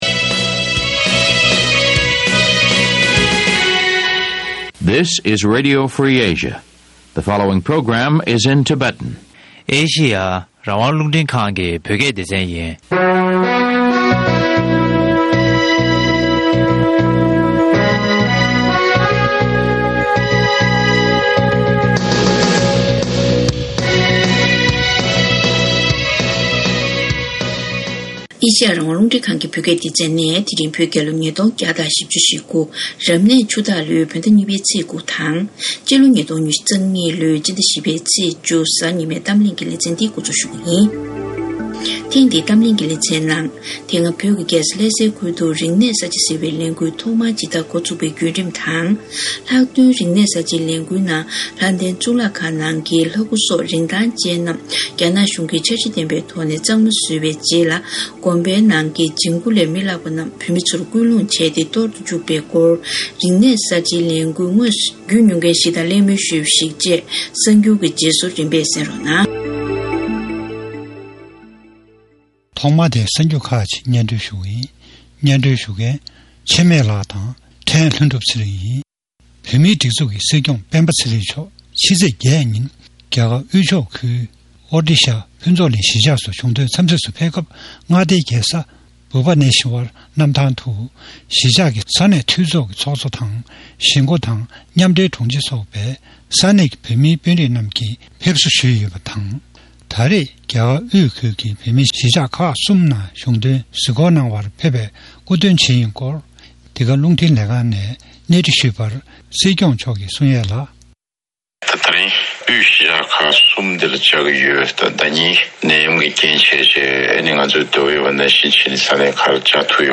ཐེངས་འདིའི་གཏམ་གླེང་གི་ལེ་ཚན་ནང་།